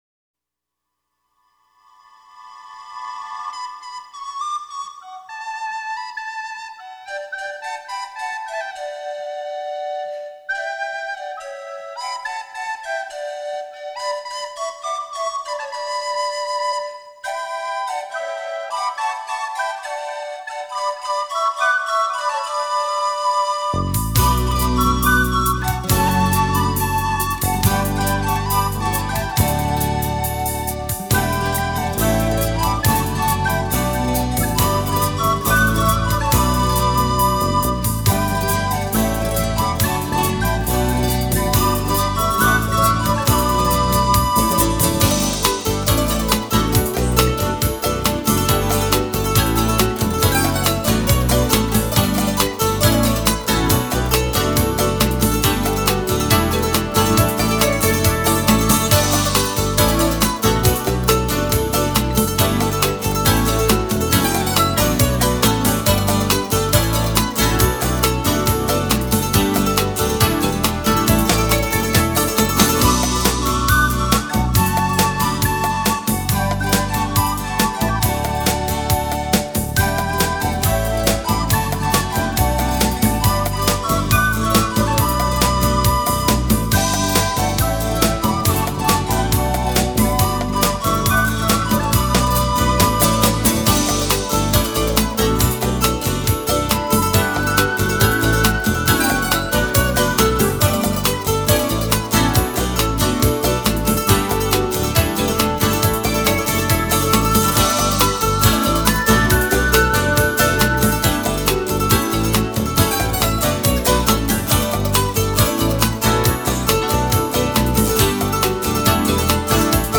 New Age or Smooth Jazz
the British guitarist